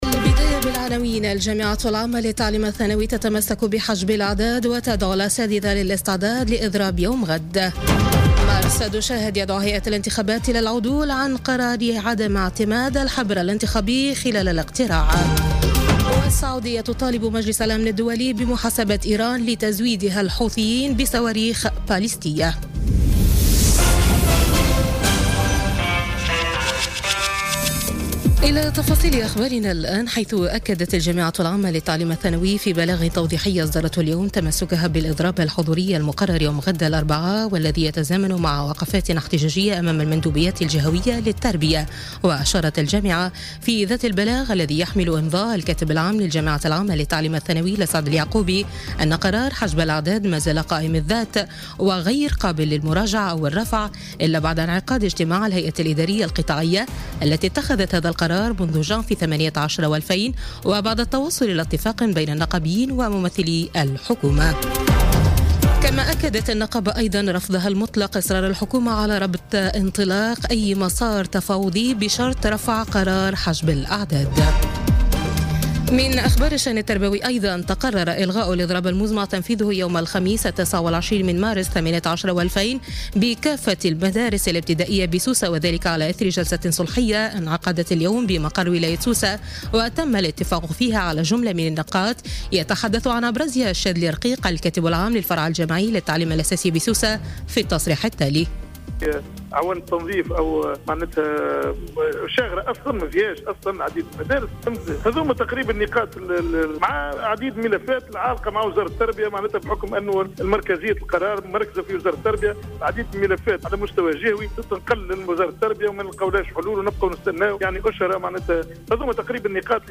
نشرة أخبار السابعة مساءً ليوم الثلاثاء 27 مارس 2018